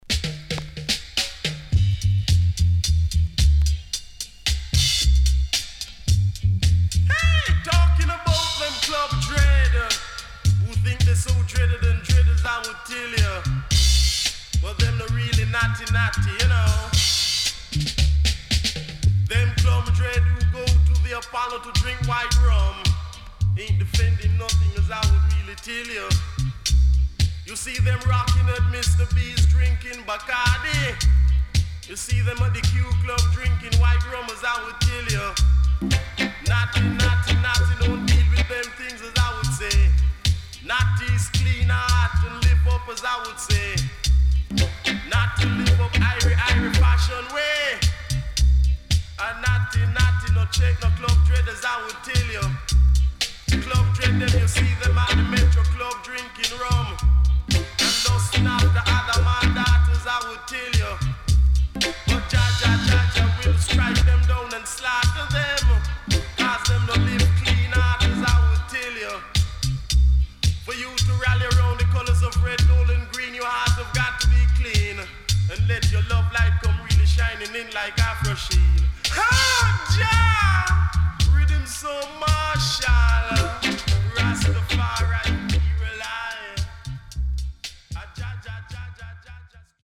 Sweet Soulful Ballad & Deejay.W-Side Good
SIDE A:少しチリノイズ入りますが良好です。